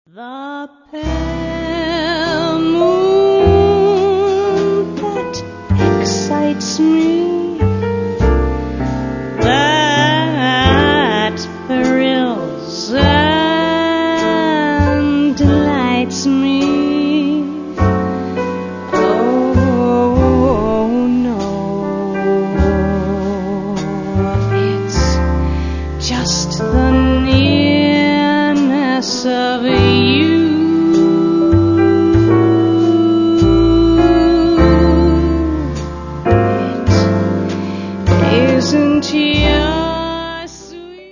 Vocals
Piano
Double Bass
Drums